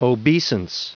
Prononciation du mot obeisance en anglais (fichier audio)
Prononciation du mot : obeisance